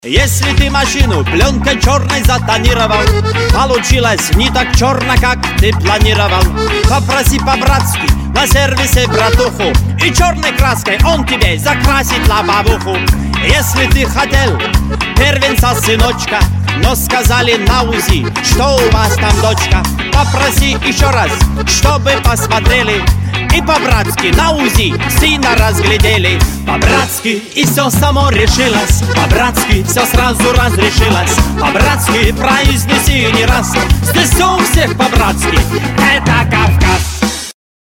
веселые
кавказские
смешные